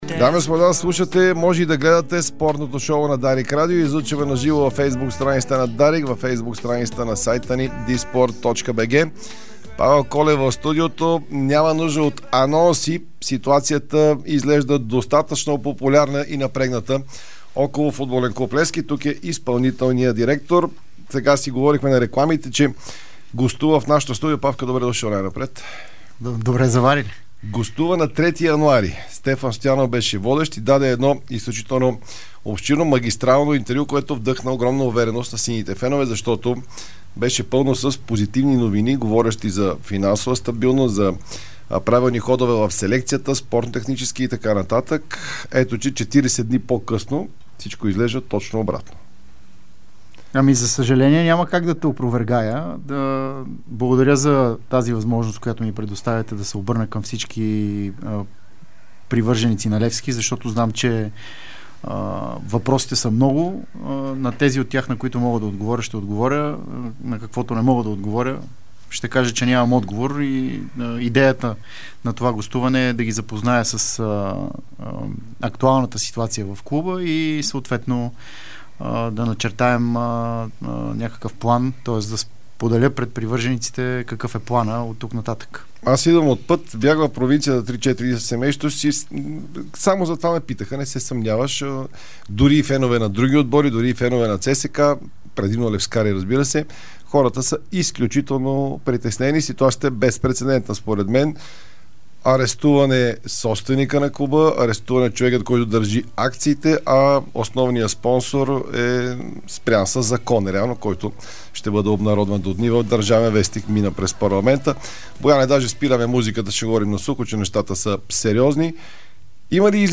Спортното шоу на Дарик радио . Той категорично отрече вариант с “тире” за Левски, тоест “сините” де вземат лиценза на друг български клуб.